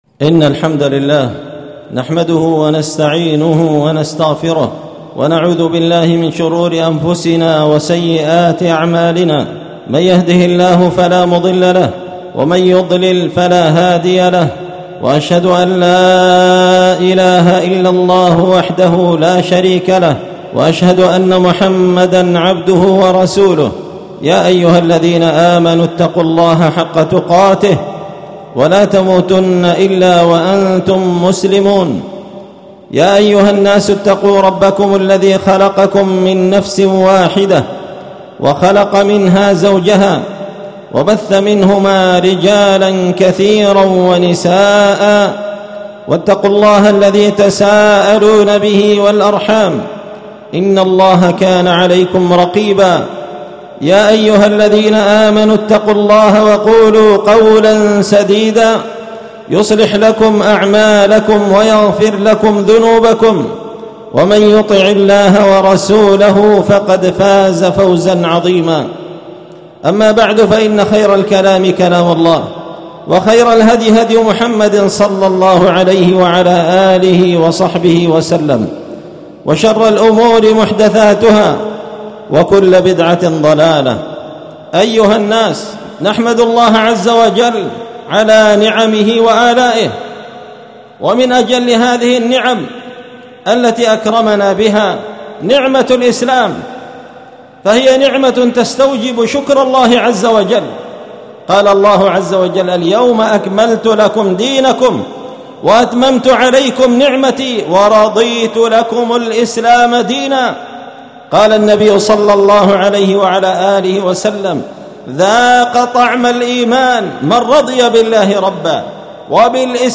قشن-المهرة-اليمن
*{خطبة عيد الفطر لعام 1447هـ }*